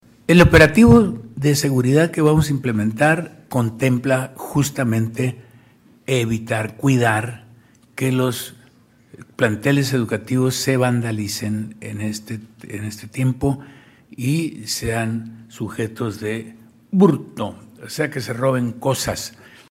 Culiacán, Sinaloa, a 23 de marzo de 2026.- Durante la conferencia de prensa “Semanera”, el gobernador del Estado, Dr. Rubén Rocha Moya, junto a la secretaria de Educación Pública y Cultura, Lic. Gloria Himelda Félix Niebla, informaron que, del 30 de marzo al 10 de abril, un total de 843 mil 491 estudiantes de todos los niveles educativos disfrutarán del periodo vacacional de Semana Santa, de acuerdo con el Calendario Escolar 2025-2026 vigente.